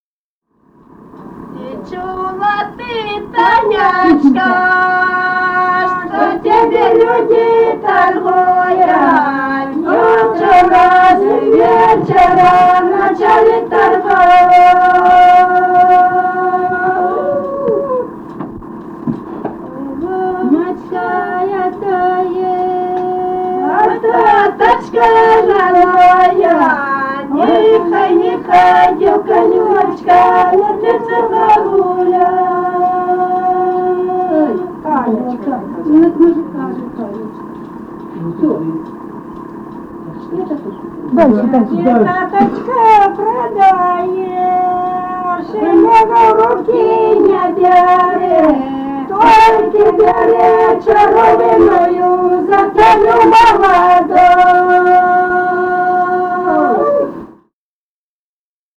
Музыкальный фольклор Климовского района 041. «Ти чуяла ты, Танечка» (свадебная).
Записали участники экспедиции